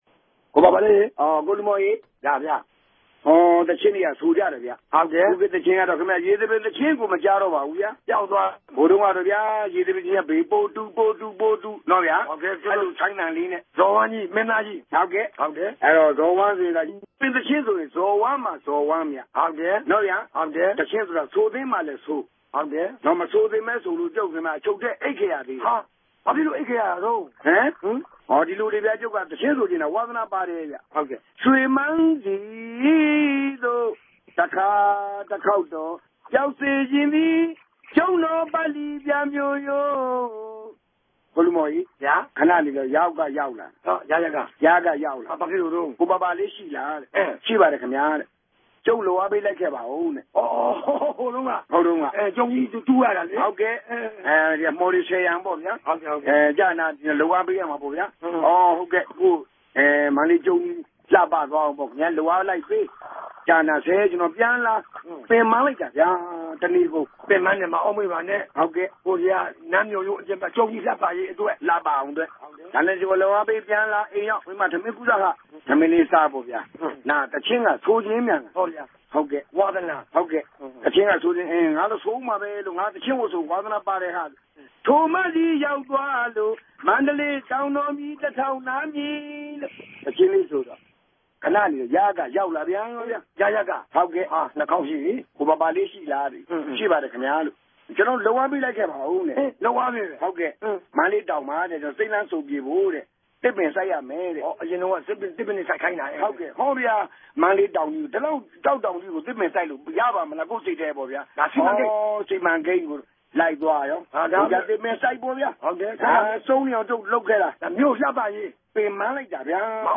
ိံိုင်ငံရေးူပက်လုံးတြေေုကာင့် ၁၉၉၆ ခိုံြစ်မြာ စစ်အစိုးရက ဖမ်းဆီးထောင်ခဵခဲ့္ဘပီး ဒီနေႛထိ ပြဲကခြင့် ပိတ်ပင်ခံထားရတဲ့ မ္ဈိံလေး္ဘမိြႚက ိံြတ်ခမ်းမြေးညီနောင် လူ႟ြင်တော် ဦးပၝပၝလေး၊ ဦးလူဇော်နဲႛ ဦးလူမော်တိုႛက အခုလို အခၝဋ္ဌကီးရက်ဋ္ဌကီး အတာသုကဿန်ကာလမြာ RFA သောတရြင်တြေ အတြက် အထူး ဖေဵာ်ေူဖပေးနေရာမြာ ဒီကနေႛ ဧ္ဘပီ ၁၆ရက်နေႛမြာလည်း ဆက်လက် ဖေဵာ်ေူဖပေးပၝတယ်။
သူတိုႛရဲ့ ဟာသအိုံပညာကို ိံြစ်ပိုင်းခြဲထား္ဘပီး ပထမပိုင်းကို မင်းတုန်းမင်းဋ္ဌကီးကို လက်တိုႛူပလိုက်ခဵင်တယ်လိုႛ အမည်ပေးထားပၝတယ်။